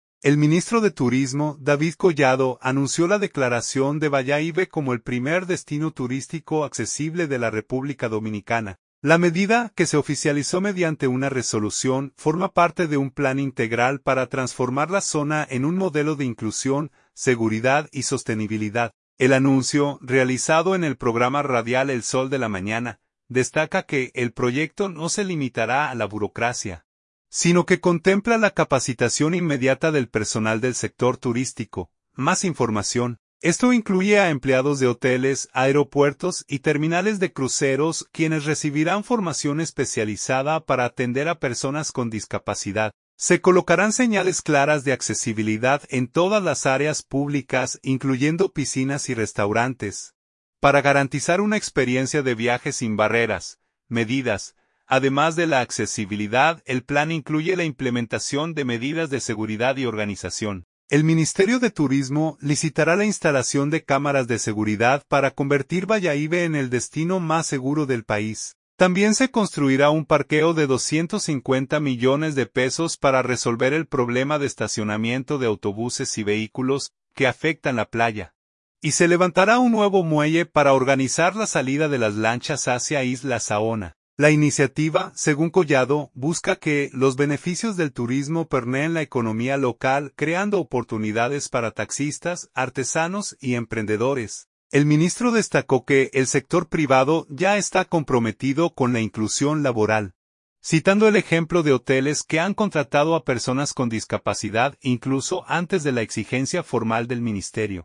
El anuncio, realizado en el programa radial "El Sol de la Mañana", destaca que el proyecto no se limitará a la burocracia, sino que contempla la capacitación inmediata del personal del sector turístico.